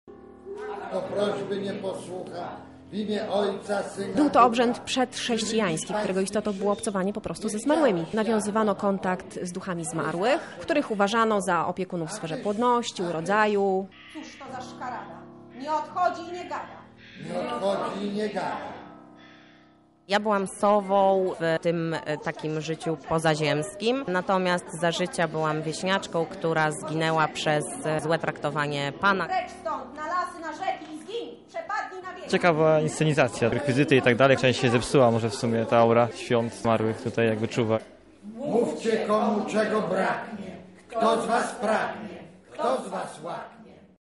Podczas obchodzenia Dziadów ludzie próbowali nawiązać kontakt ze zmarłymi. Pracownicy Centrum Kultury przebrani za Mickiewiczowskie postacie, wspólnie z gośćmi odczytali II część dramatu.